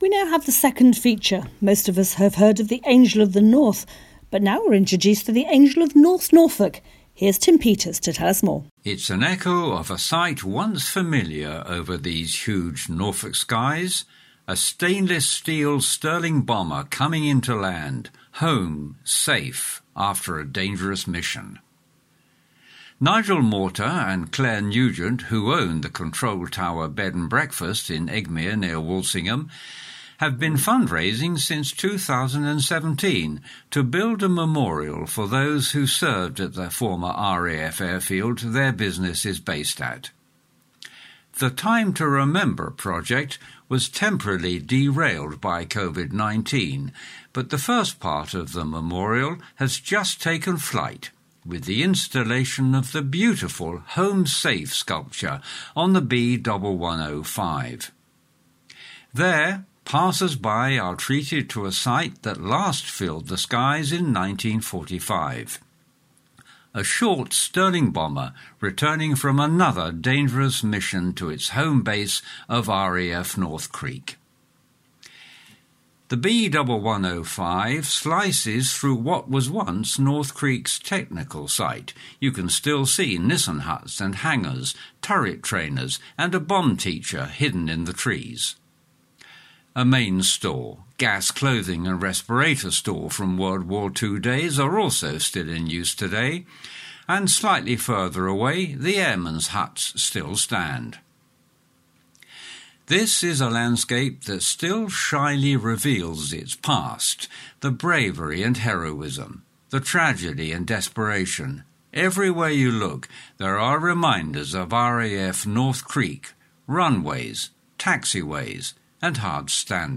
Chatterbox Norwich Talking Newspaper Petersen House 240 King Street Norwich NR1 2TT